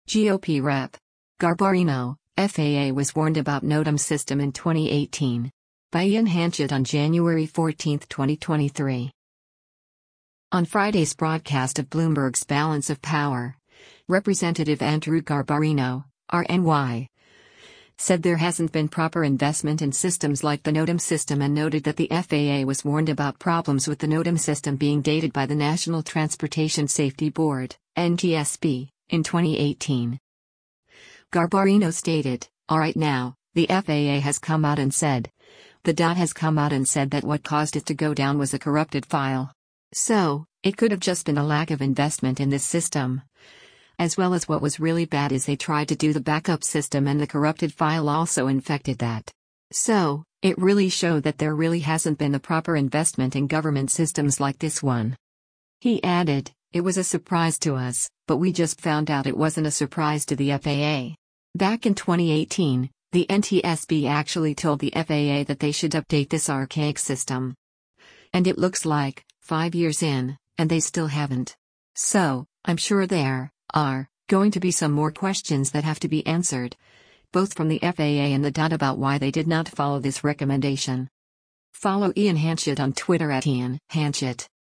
On Friday’s broadcast of Bloomberg’s “Balance of Power,” Rep. Andrew Garbarino (R-NY) said there hasn’t been proper investment in systems like the NOTAM system and noted that the FAA was warned about problems with the NOTAM system being dated by the National Transportation Safety Board (NTSB) in 2018.